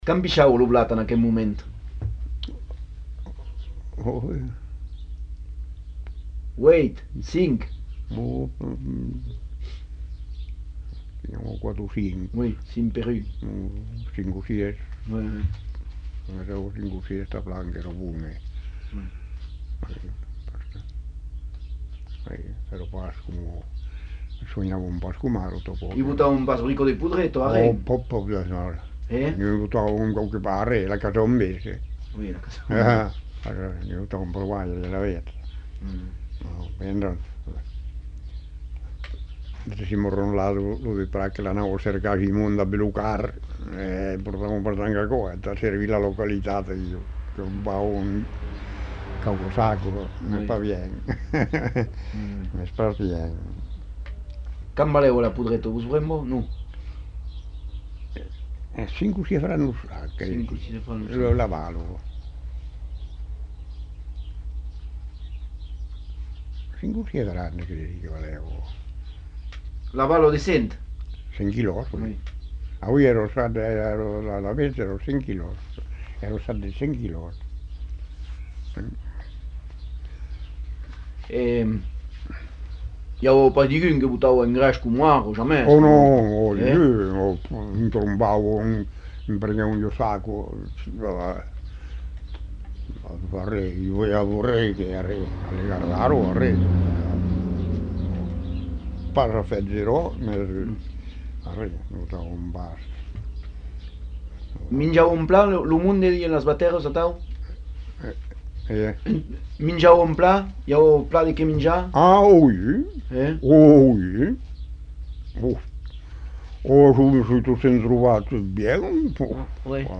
Lieu : Simorre
Genre : témoignage thématique